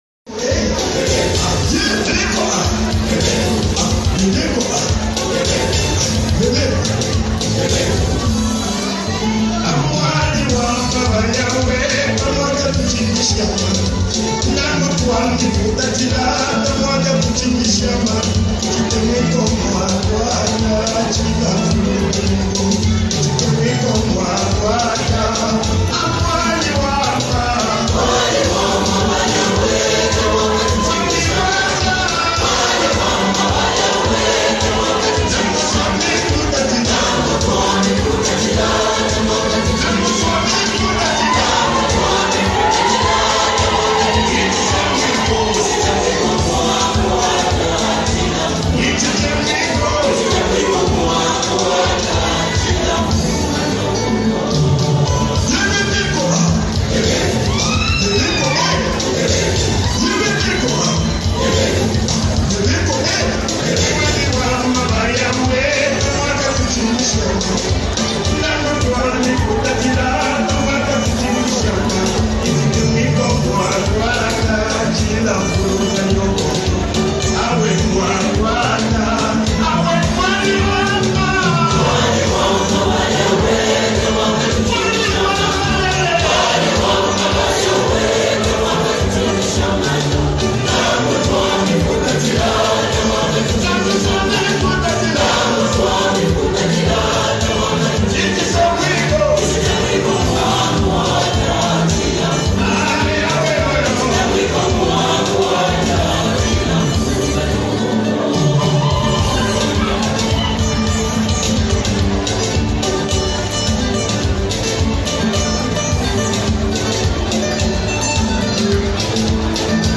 EXALTATION WORSHIP ANTHEM | 2025 ZAMBIA GOSPEL